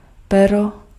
Ääntäminen
Vaihtoehtoiset kirjoitusmuodot (vanhahtava) fether Synonyymit feathering feathers horsefeathers Ääntäminen US RP : IPA : [ˈfɛðə] IPA : /ˈfɛð.ə(ɹ)/ GenAm: IPA : [ˈfɛðɚ] Tuntematon aksentti: IPA : [ˈfɛ.ðɝ]